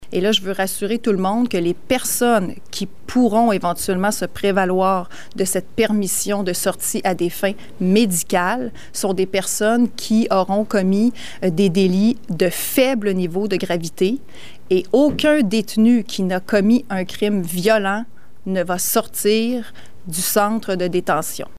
C’est la vice-première ministre Geneviève Guilbault qui en a fait l’annonce lors du point de presse des autorités québécoises, mercredi après-midi.
Geneviève Guilbault, qui est aussi ministre de la Sécurité publique, a par ailleurs fait savoir que certains détenus, qui ont des peines restantes de moins de 30 jours, pourraient sortir plus tôt que prévu. Des permissions de sorties à des fins médicales pourront aussi être accordées, mais pas à tout le monde comme l’indique la ministre Guibault :